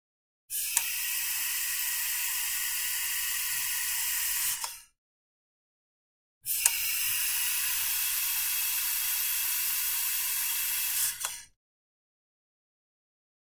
Звуки кислорода: открыли и закрыли подачу